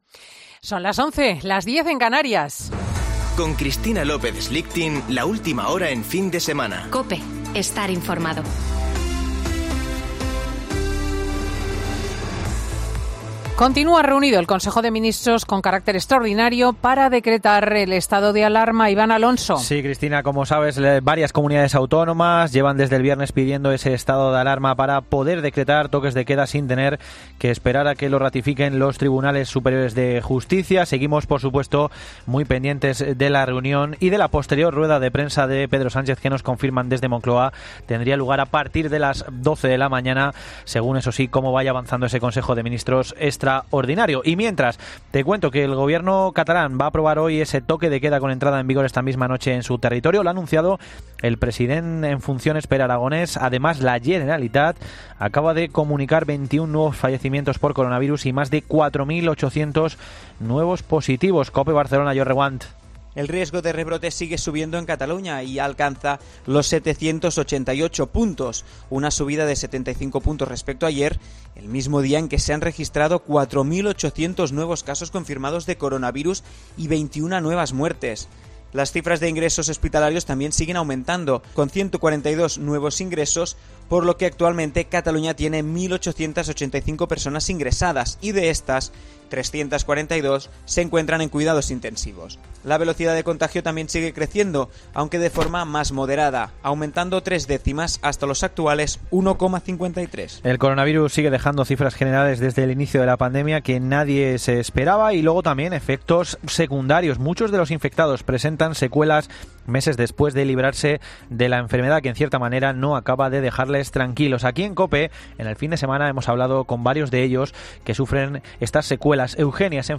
Boletín de noticias COPE del 25 de octubre de 2020 a las 11.00 horas